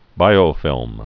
(bīō-fĭlm)